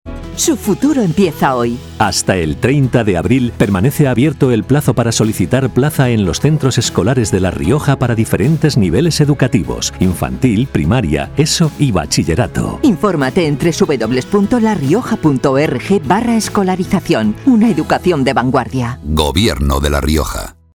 Elementos de Campaña Cuña radiofónica Cuña 20".